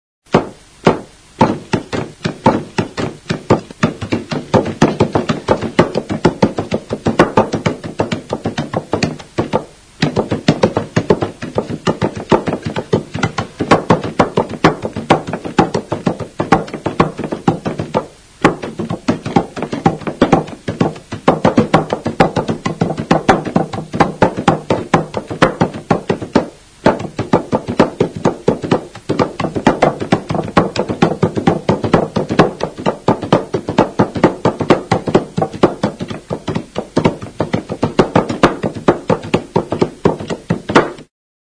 Idiophones -> Struck -> Directly
JOALDIA. Ergoiengo taldea. Oiartzun, Sarobe baserria, 1999.